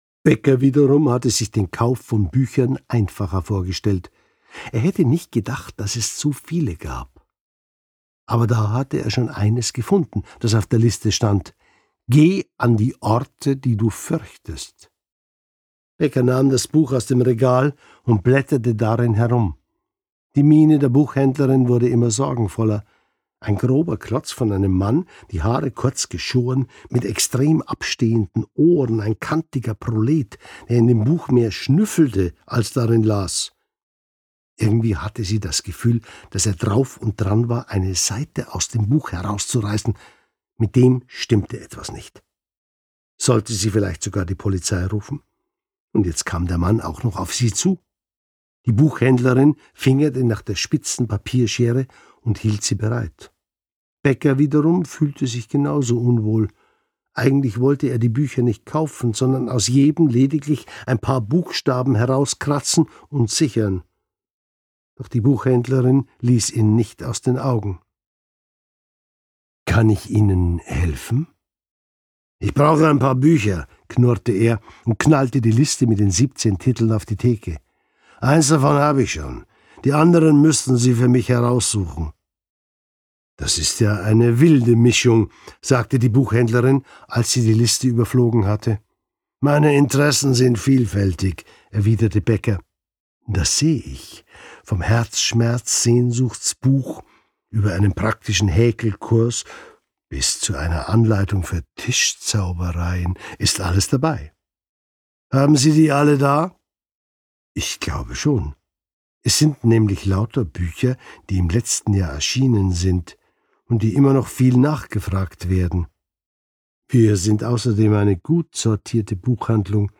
Kommissar Jennerwein darf nicht sterben Roman Jörg Maurer (Autor) Jörg Maurer (Sprecher) Audio-CD 2023 | 2.